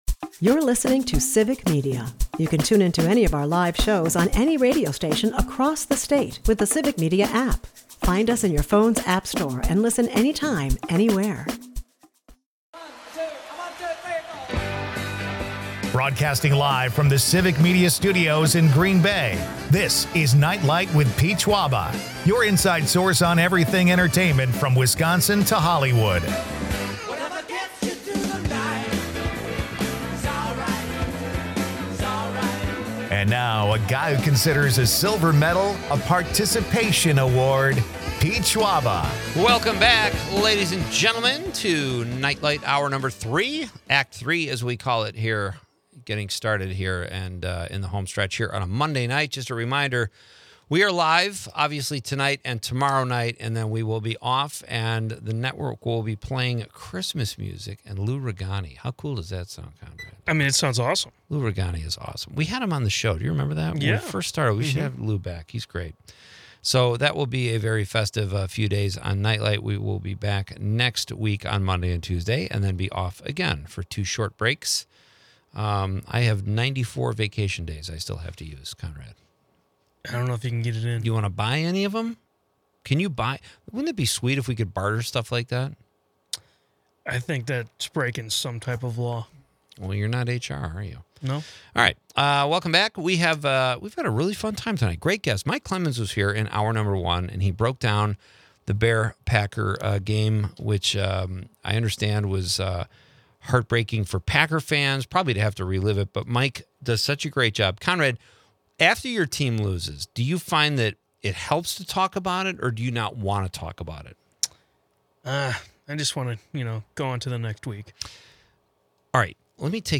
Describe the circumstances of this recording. With listener questions peppering the conversation, the episode is a spirited mix of mob lore, personal anecdotes, and a few laughs about everything from NFL strategy to ghostly bagpipes in Milwaukee.